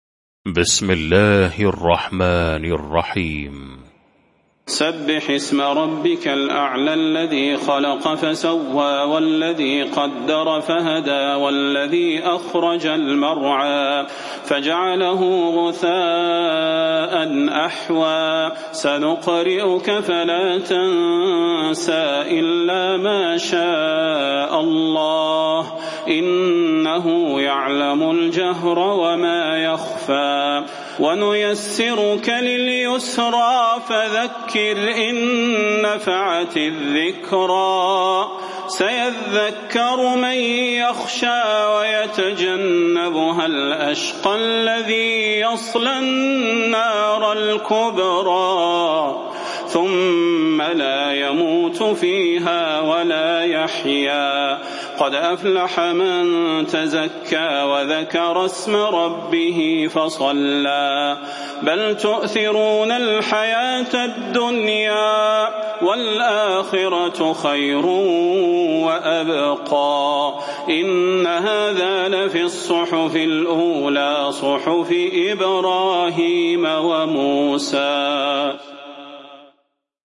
المكان: المسجد النبوي الشيخ: فضيلة الشيخ د. صلاح بن محمد البدير فضيلة الشيخ د. صلاح بن محمد البدير الأعلى